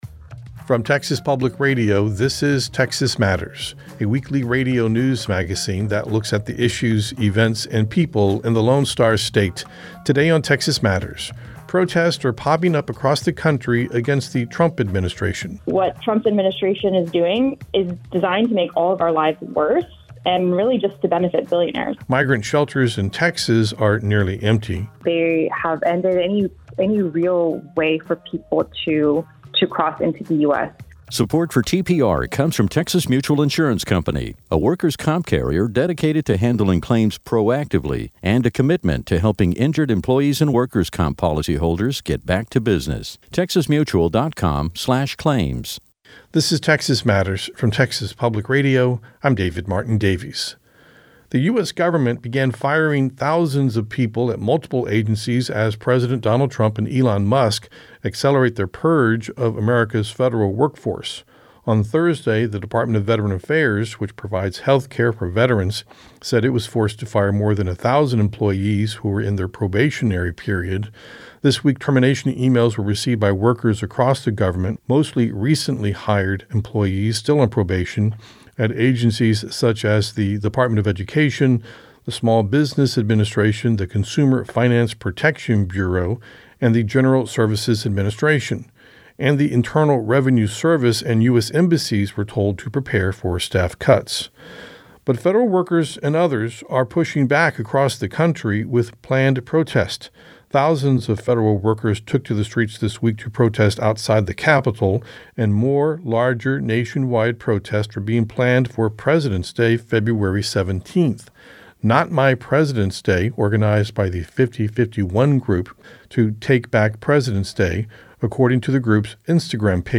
Texas Matters is a statewide news program that spends half an hour each week looking at the issues and culture of Texas.